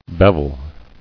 [bev·el]